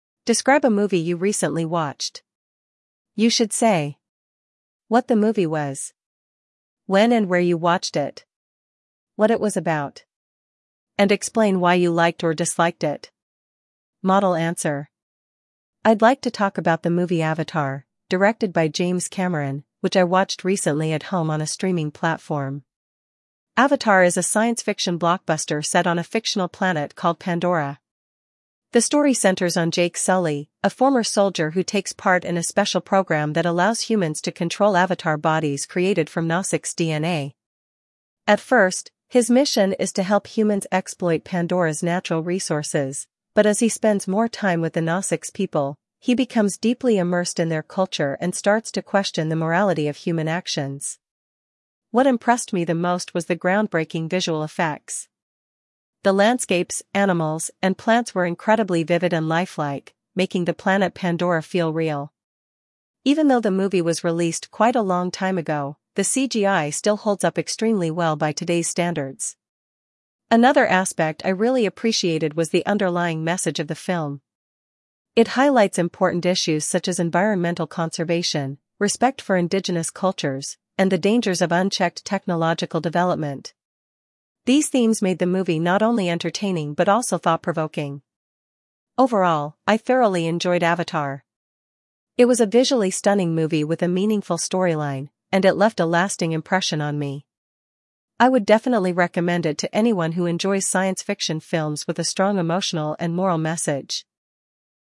IELTS Speaking Part 2
Model Answer